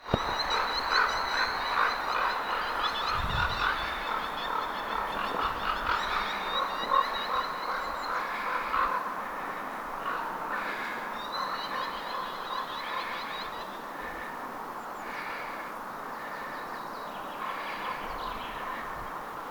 pikkujalohaukka pesällään ääntelee?
arvatenkin_pikkujalohaukan_pesan_laheisyydessa_sen_aantelya_falcol.mp3